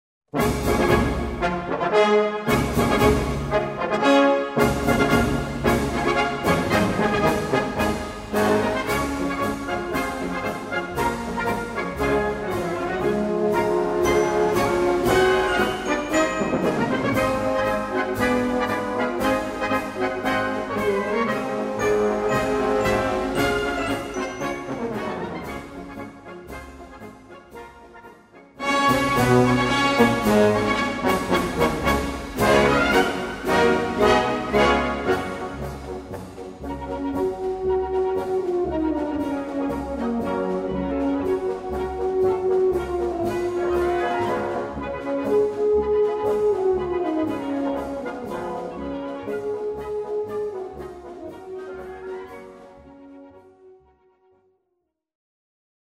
Gattung: Straßenmarsch
Besetzung: Blasorchester